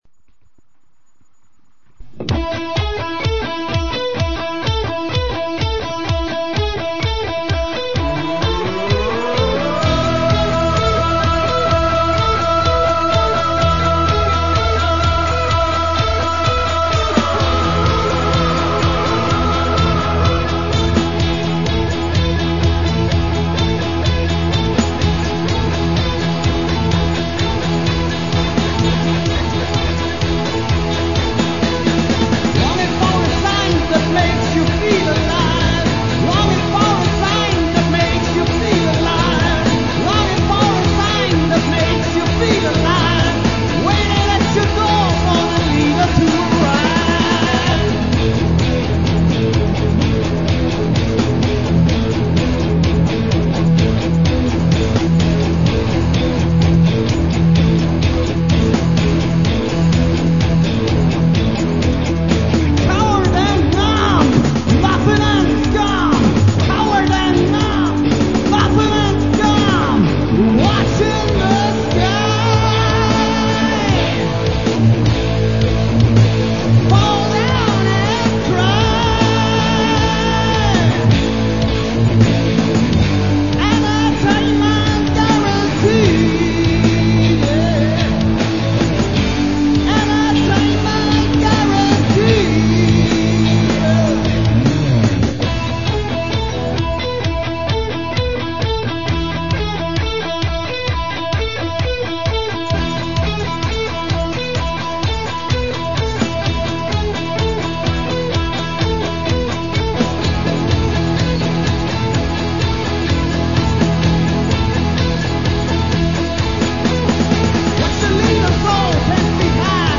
Heavy Rockband
Drums
Vocals
Guitar